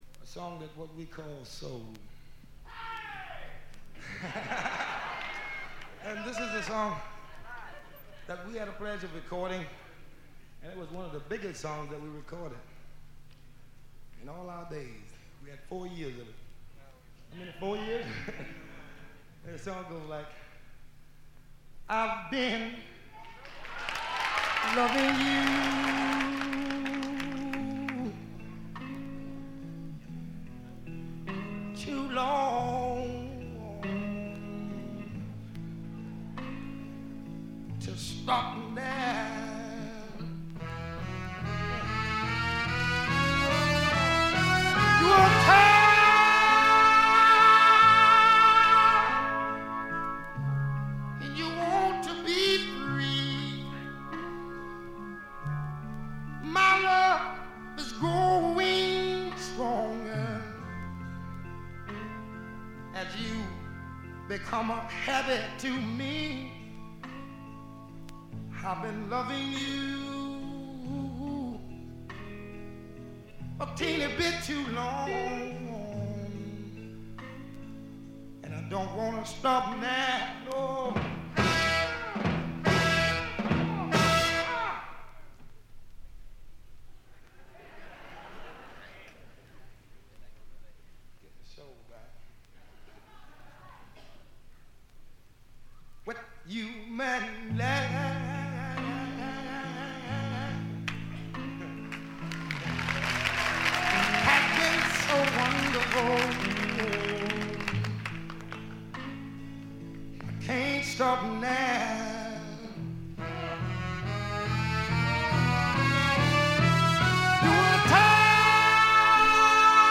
わずかなノイズ感のみ。
魂のライヴ・パフォーマンスが収められた真の名盤。
試聴曲は現品からの取り込み音源です。
Recorded at the Olympia Theatre, Paris; March 21, 1967.